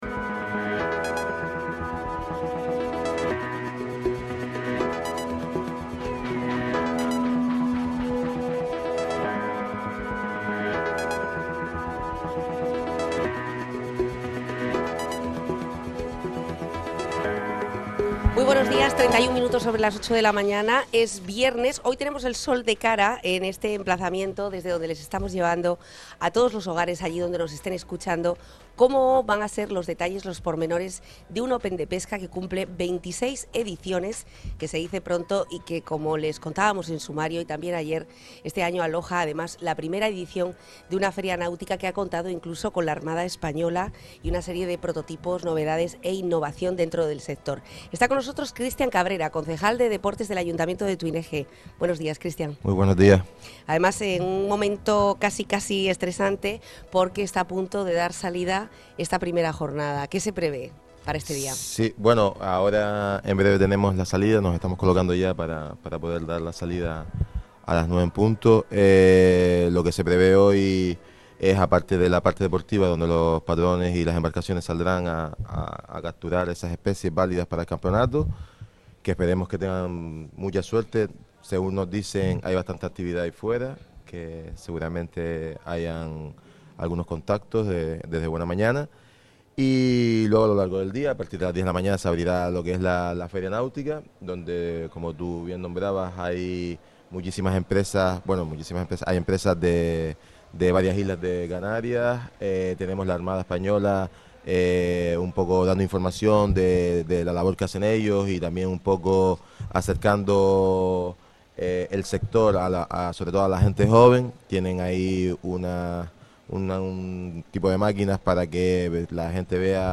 Así lo ha explicado el concejal de Deportes del Ayuntamiento de Tuineje Christian Cabrera en los micrófonos de Onda Fuerteventura. En el Especial El Magacín desde Gran Tarajal, Cabrera ha subrayado lo que significa para el pueblo y el municipio la vuelta del Open, las características de este año y el trabajo que han realizado.